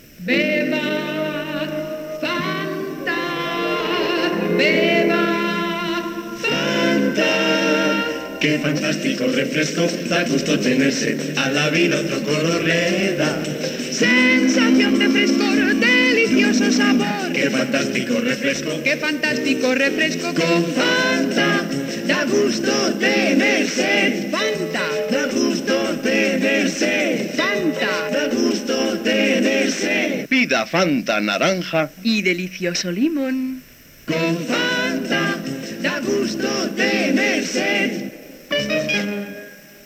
Anunci Fanta
L'enregistrament de l'anunci es va fer als Estudios Celada de Madrid.